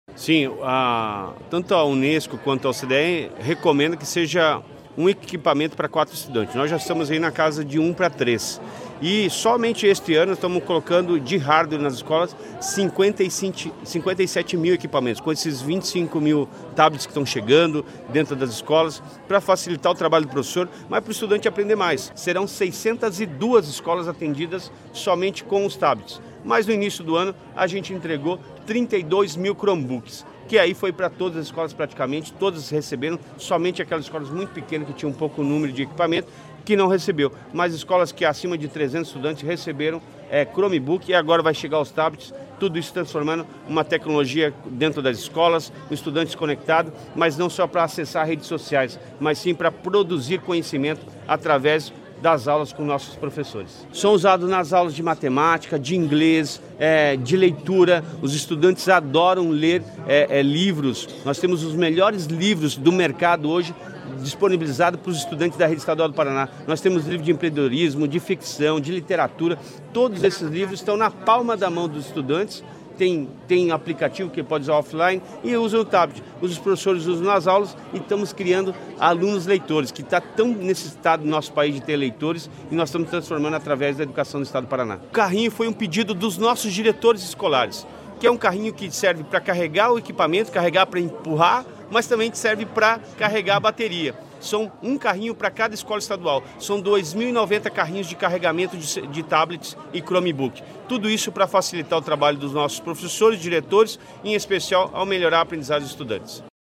Sonora do secretário da Educação, Roni Miranda, sobre a entrega de 25 mil tablets para escolas estaduais